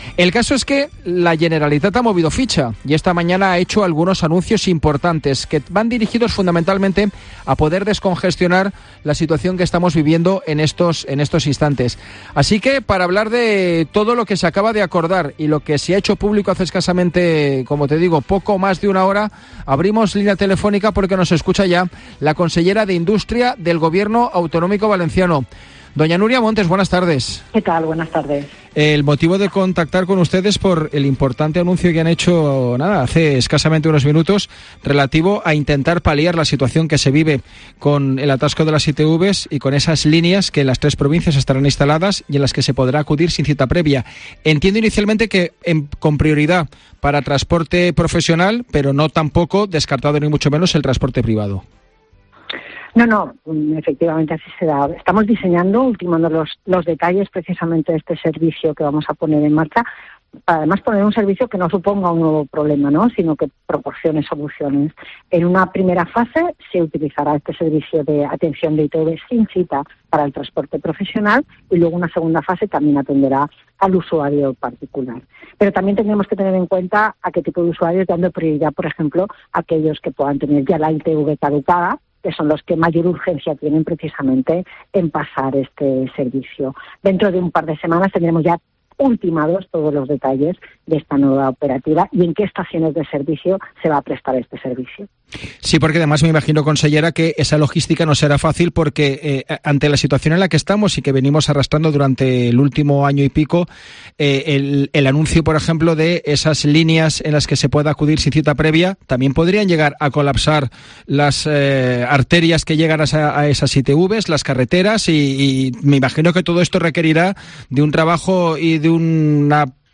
La consellera de Industria, Núria Montes, atiende la llamada de COPE para analizar las propuestas que plantean para Sitval, la empresa pública que gestiona las estaciones